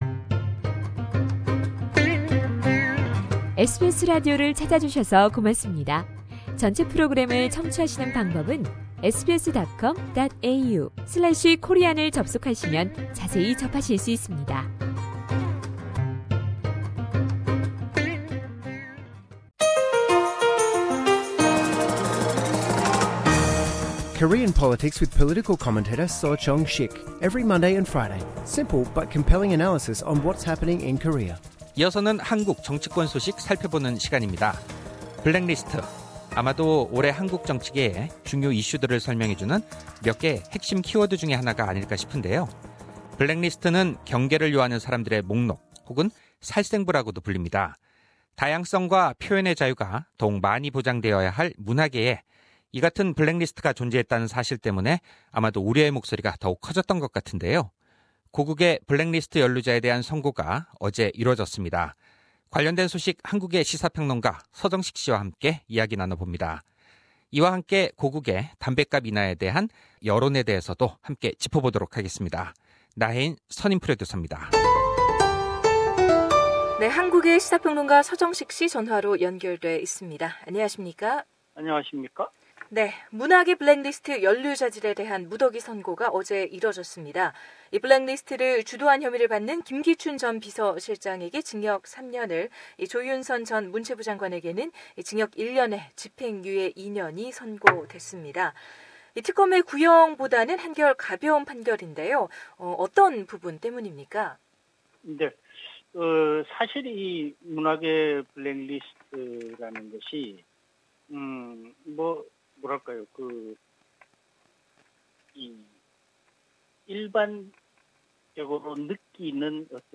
상단의 팟캐스트를 통해 전체 인터뷰를 들으실 수 있습니다.